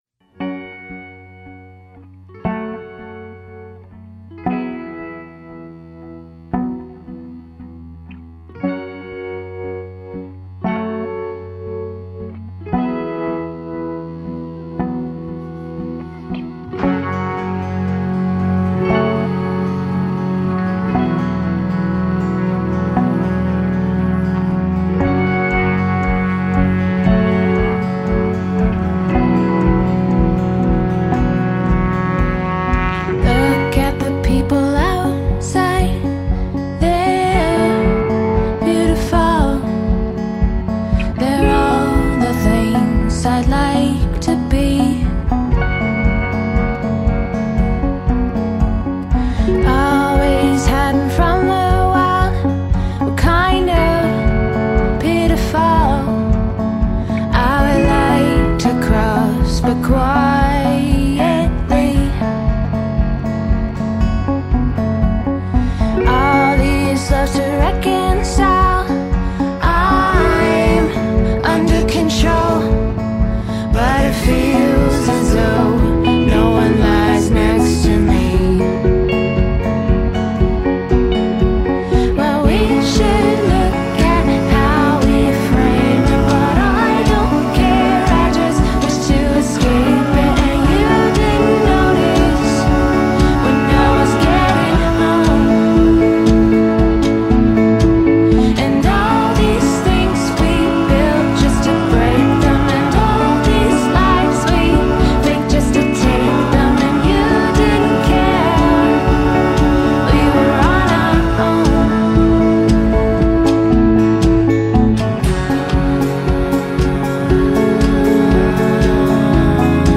Toronto six piece indie-pop collective